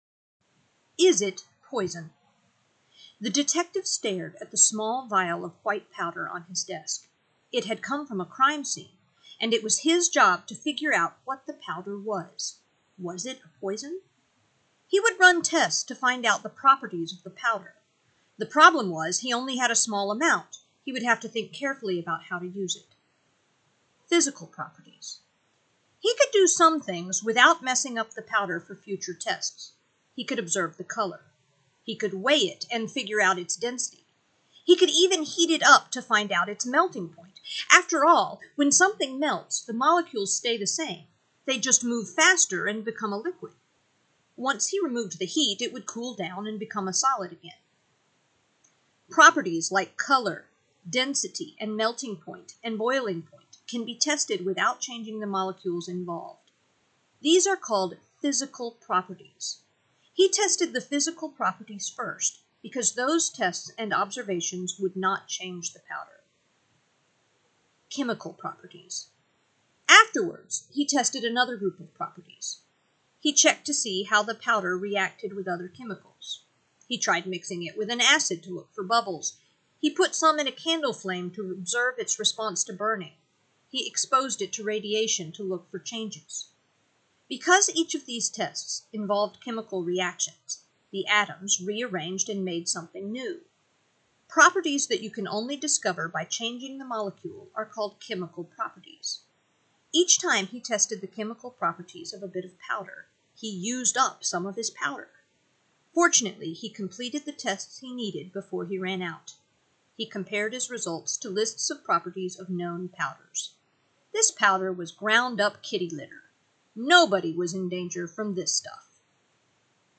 Audio (Read-Aloud) Version of